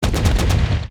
ZombieSkill_SFX
sfx_skill 05_3.wav